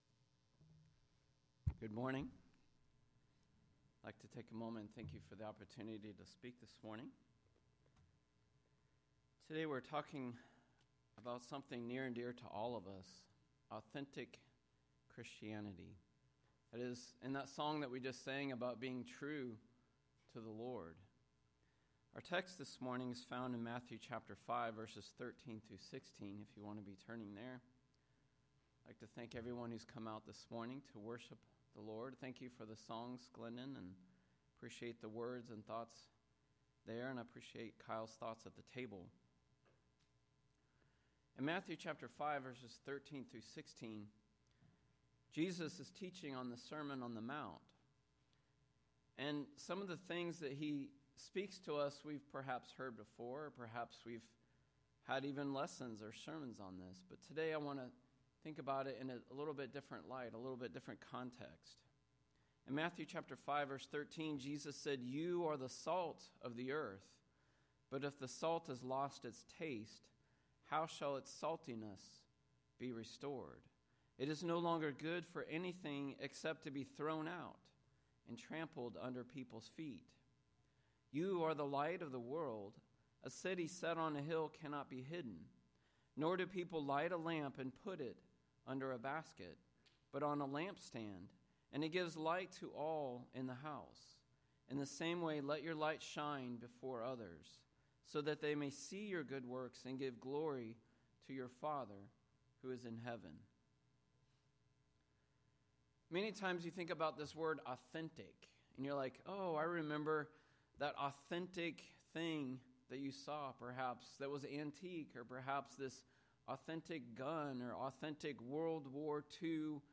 The sermon’s goal is to exhort us to be truly authentic Christians.